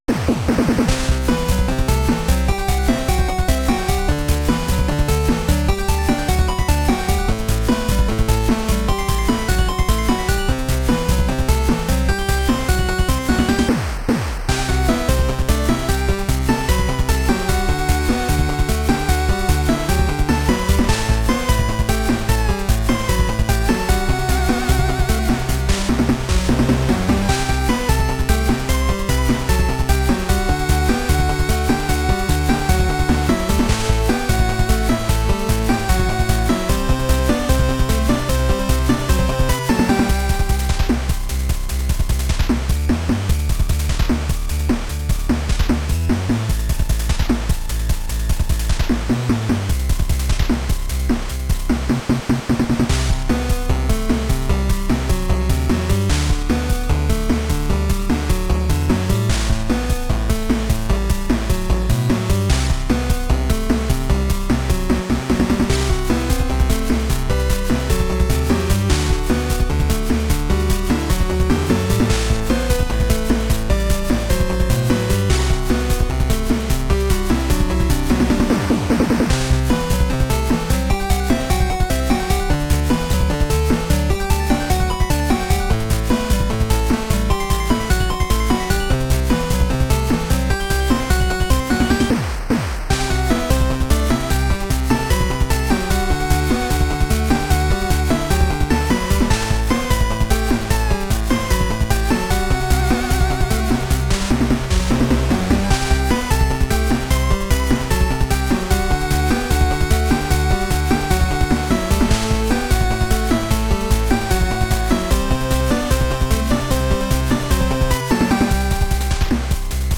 This is part 2 of Journey, a collection of chiptune songs I've made over the past 9 years.
Modules used are 2a03+VRC6, mostly.Inspired by a plethora of genres.